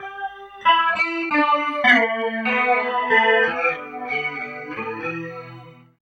29 GUIT 1 -R.wav